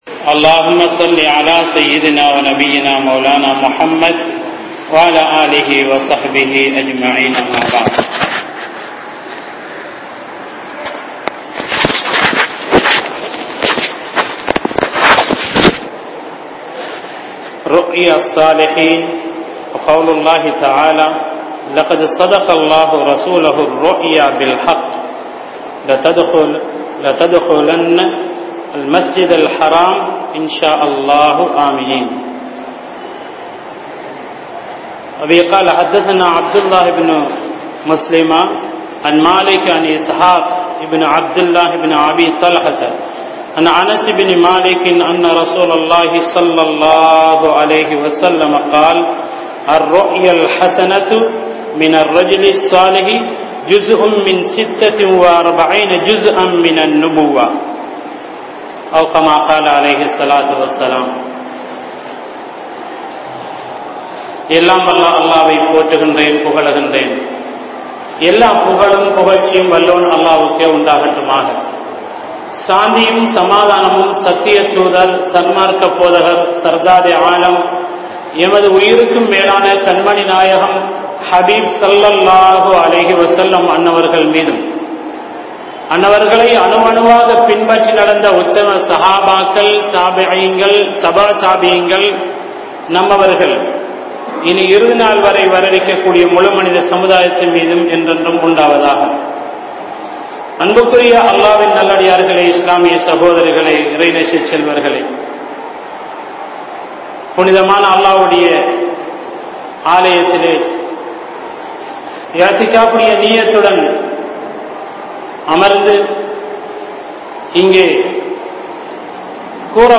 Kanavuhal(Dreams) | Audio Bayans | All Ceylon Muslim Youth Community | Addalaichenai